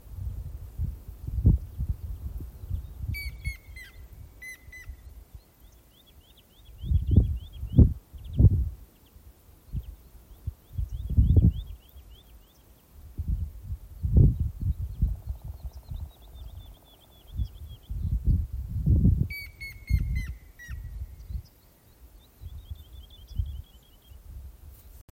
Pelēkā dzilna, Picus canus
Administratīvā teritorijaAlūksnes novads
StatussDzied ligzdošanai piemērotā biotopā (D)